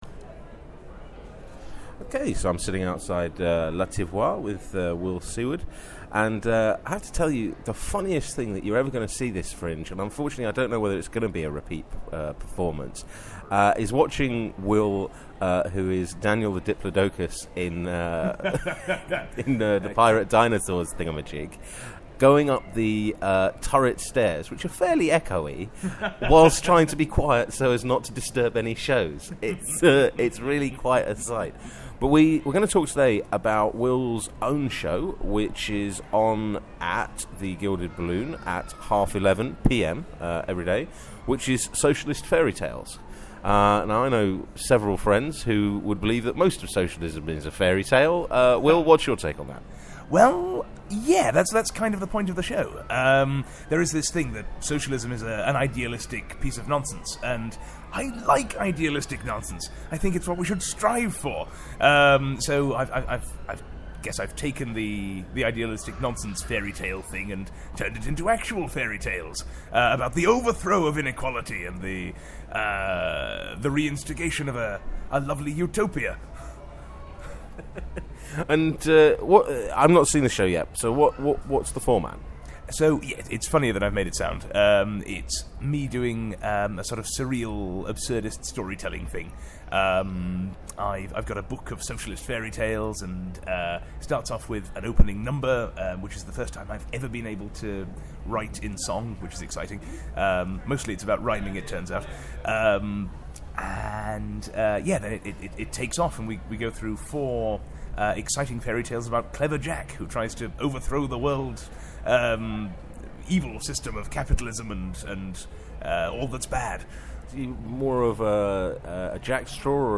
Edinburgh Audio 2013
Interview